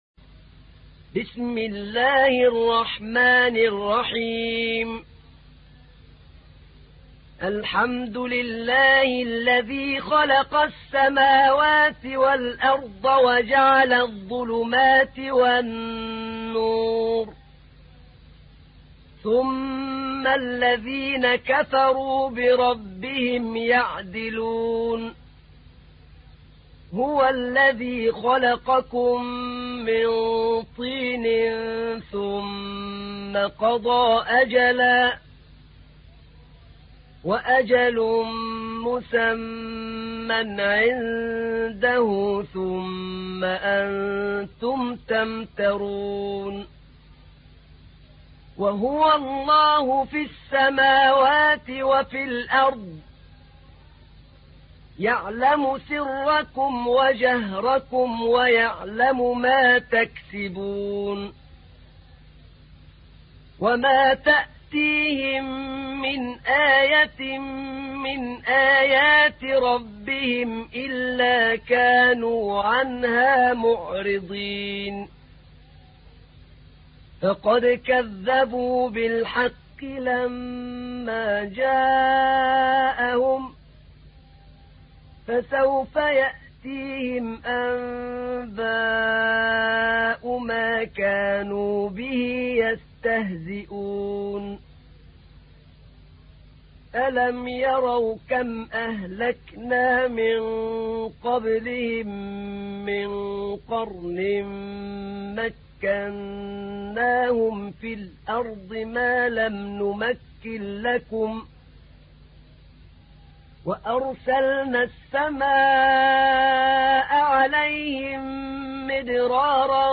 تحميل : 6. سورة الأنعام / القارئ أحمد نعينع / القرآن الكريم / موقع يا حسين